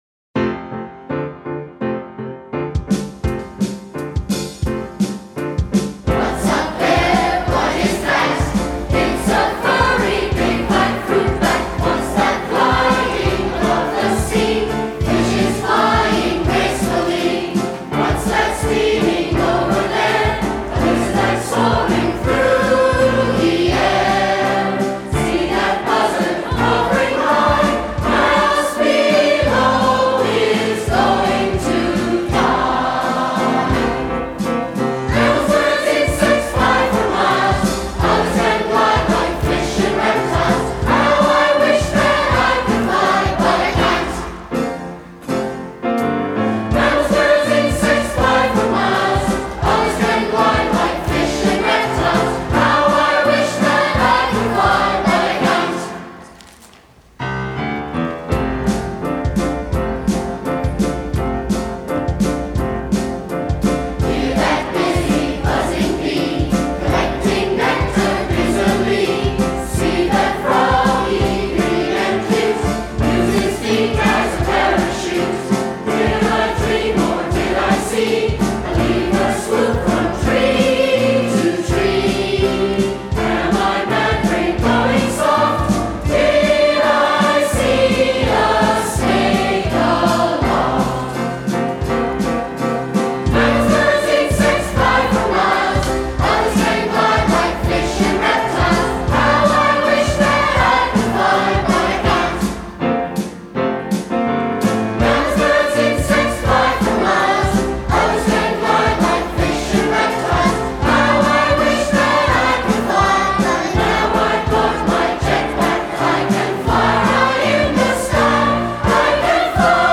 Below you can hear the 2007 Festival Chorus performing Lifetime: Songs of Life and Evolution.